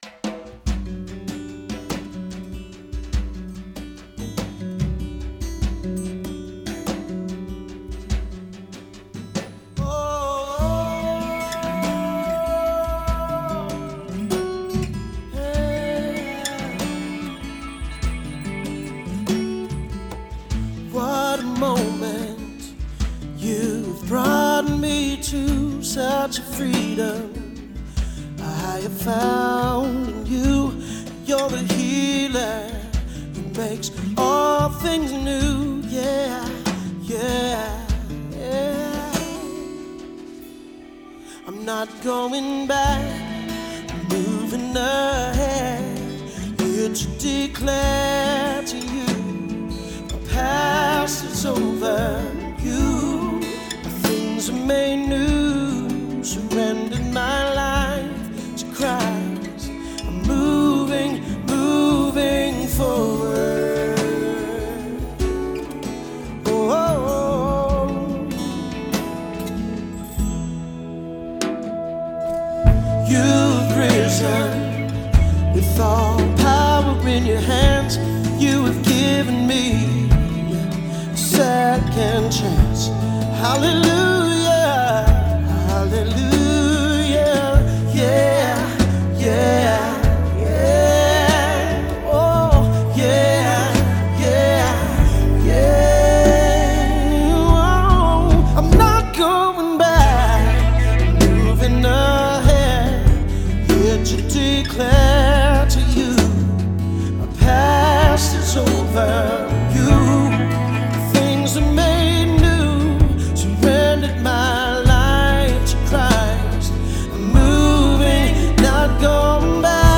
Closing Hymn: (Smile)